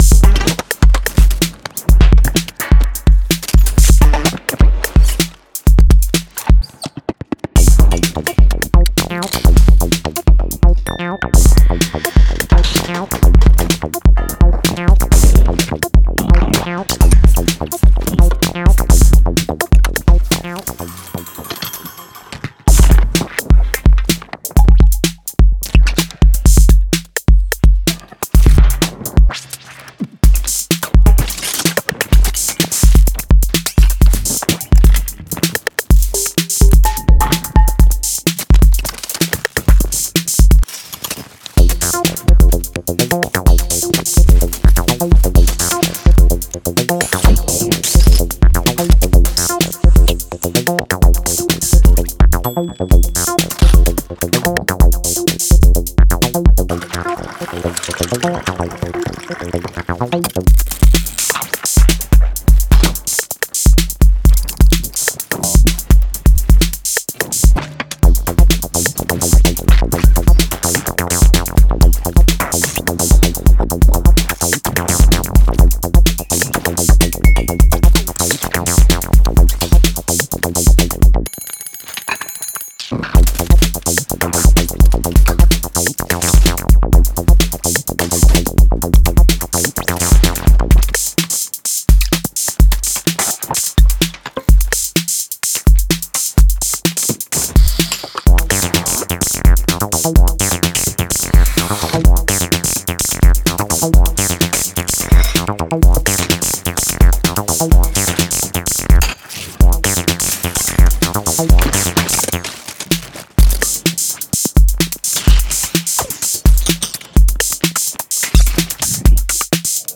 Genre:IDM
その結果、有機的でありながら予測不能なパーカッシブループが生まれ、無機質なドラムマシンからの脱却に最適です。
100 Glitched Percussion Loops
05 303 Acid Loops